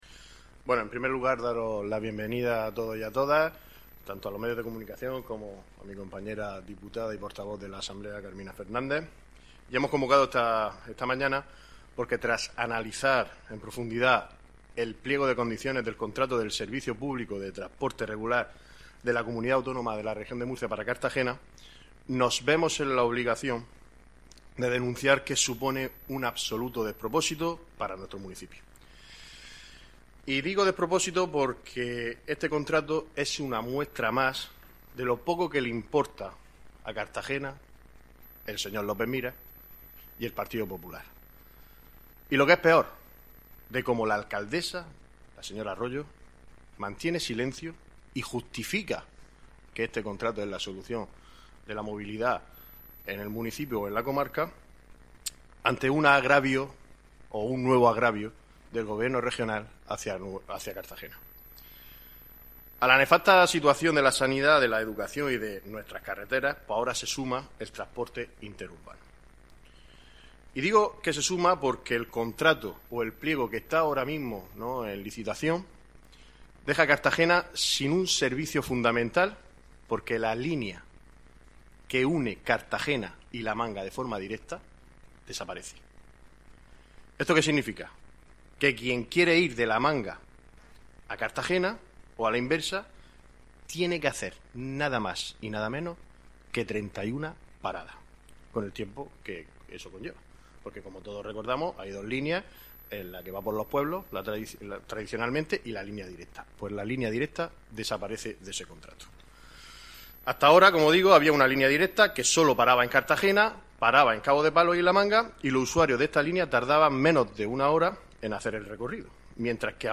Audio: Rueda de prensa PSOE Cartagena (MP3 - 9,26 MB)